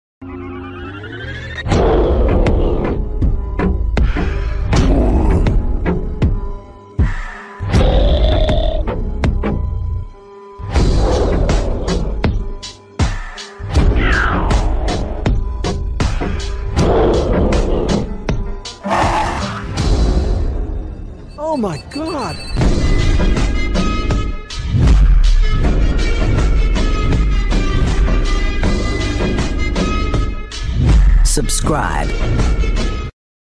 Deep In The Forest, Zombie Sound Effects Free Download